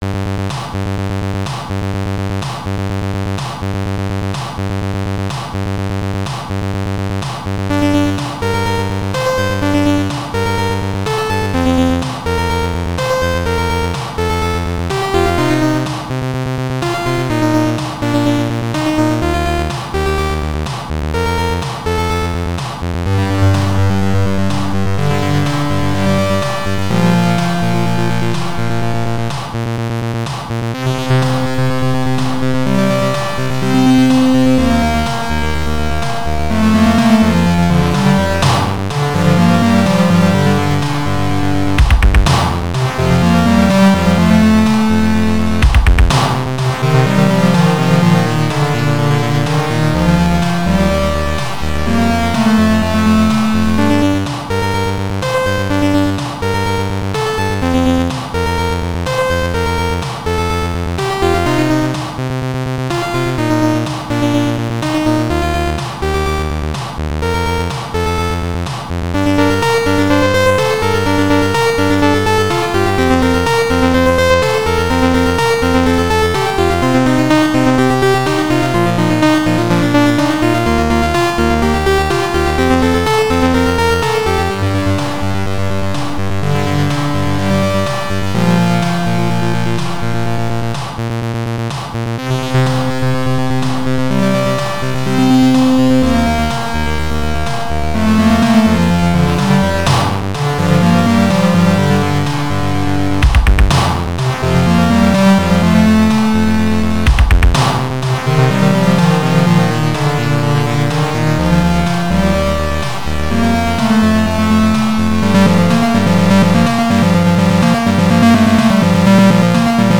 Synthesis Module
Type Synth 4.0